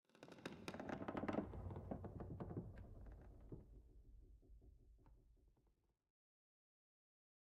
pale_hanging_moss14.ogg